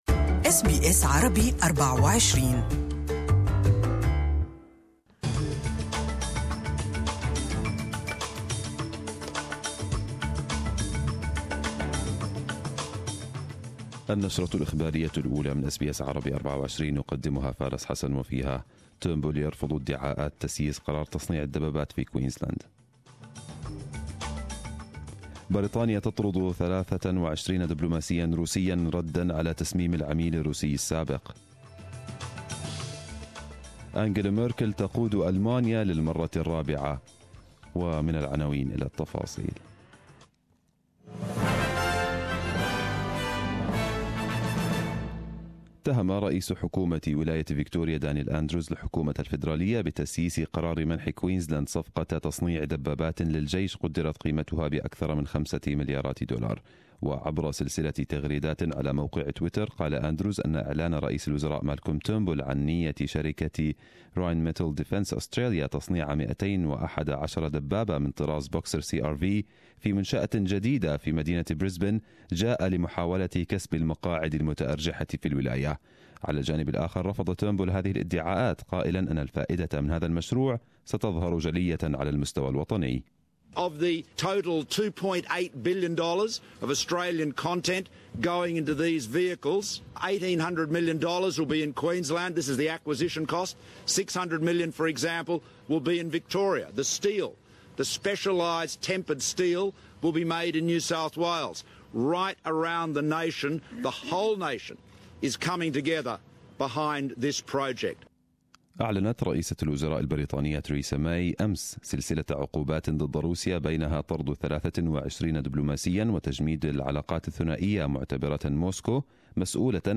Arabic News Bulletin 15/03/2018